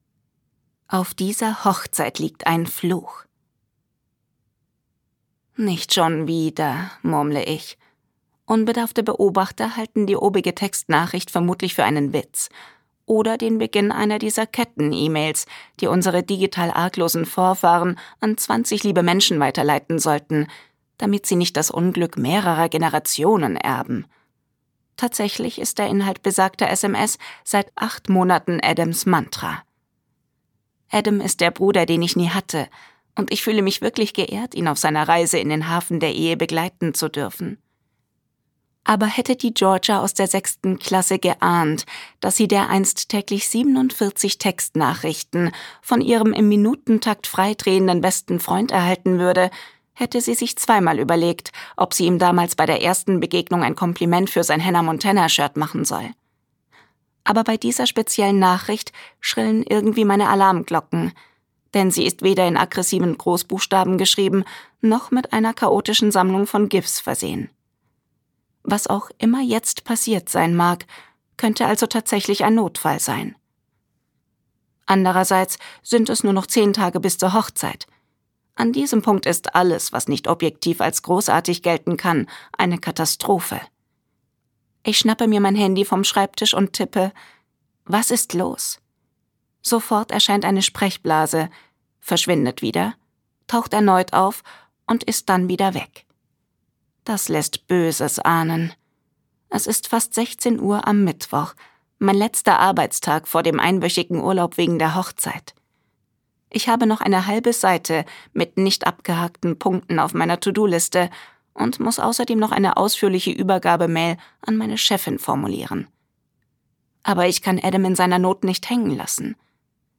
Gekürzt Autorisierte, d.h. von Autor:innen und / oder Verlagen freigegebene, bearbeitete Fassung.